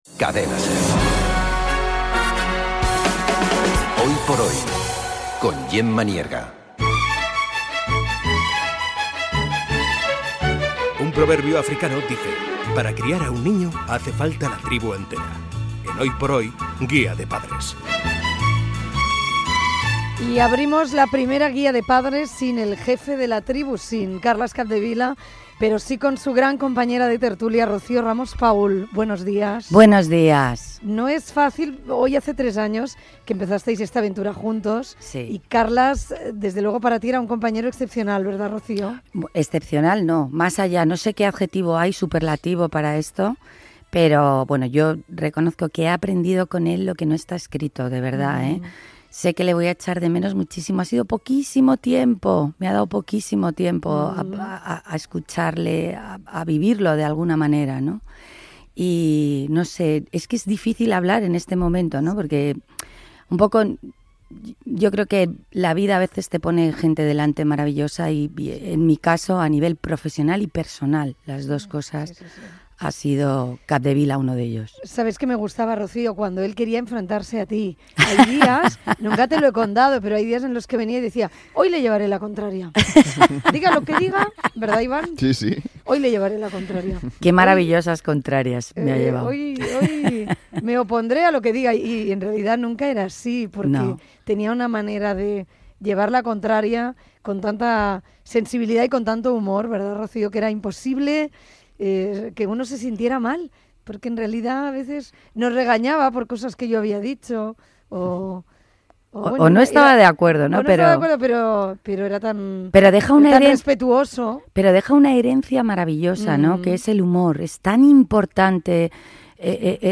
amb intervenció de diverses persones que valoren la seva feina i el seu tracte personal
Info-entreteniment